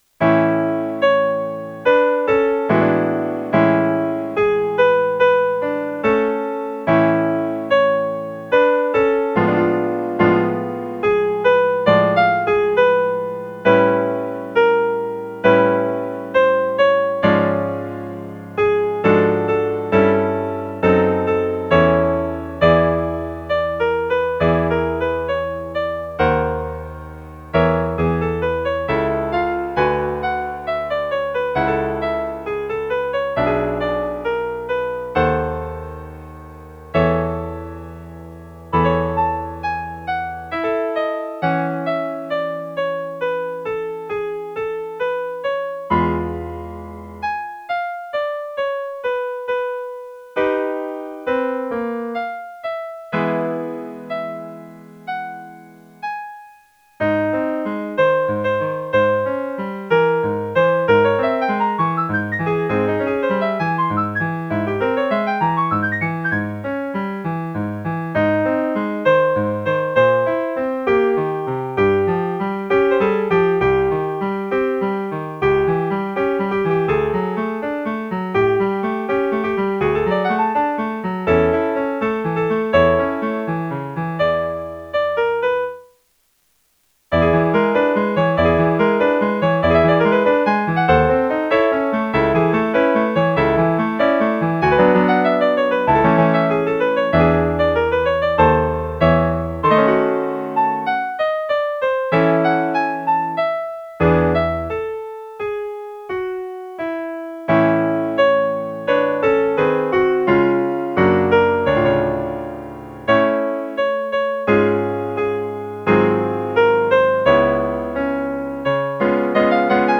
''Ob du nun ruhst'' - 피아노 반주